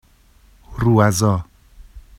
[ruazɑ] n grandchild